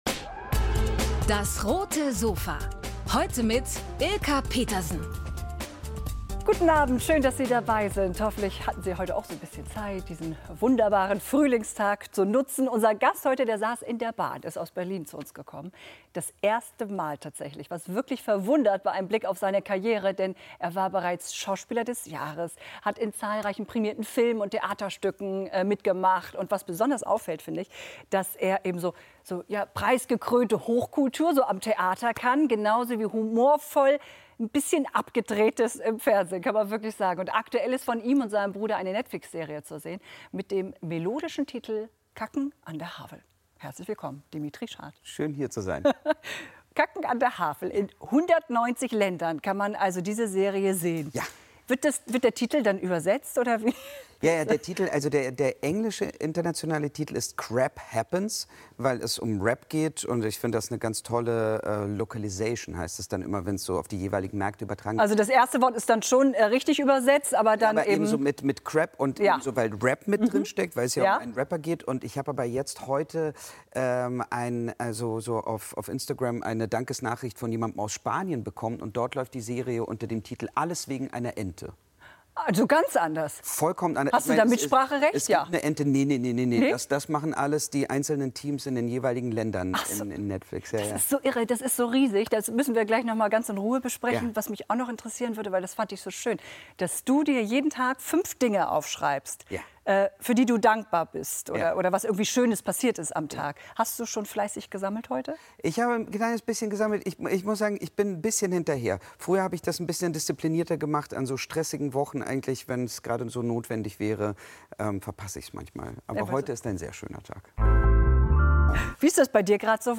DAS! - täglich ein Interview